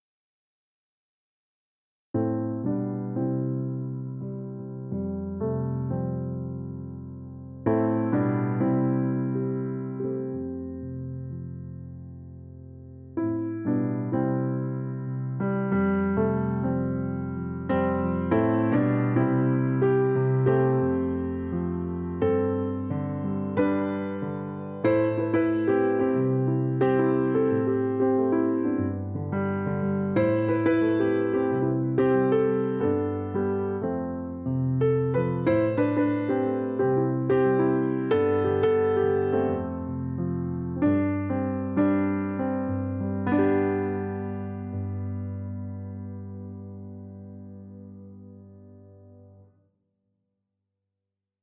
Hintergrundpianistin
Hintergrundmusik bedeutet für mich, einfühlsam Klavier zu spielen und intuitiv auf den jeweiligen Anlass einzugehen.
Einige Hörbeispiele für solche aus dem Moment heraus entstandenen Improvisationen: